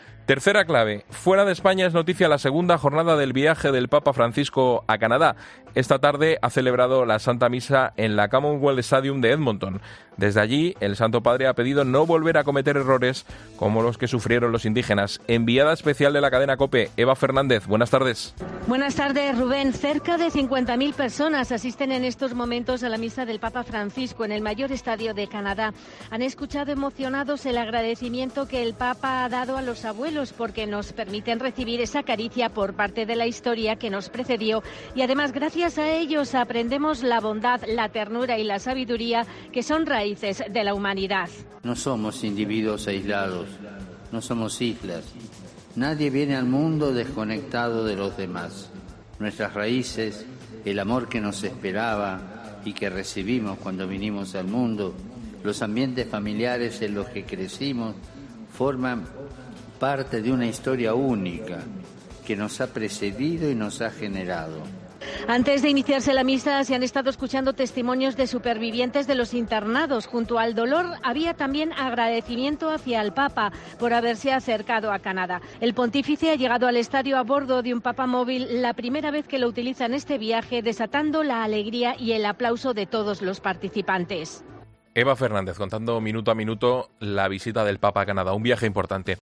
El Santo Padre ha empezado su segunda jornada en Canadá celebrando la Santa Misa en el Commonwealth Stadium ante la presencia de más de 60 mil personas